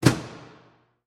Звуки рубильника
На этой странице представлена коллекция звуков рубильника — от четких металлических щелчков до глухих переключений.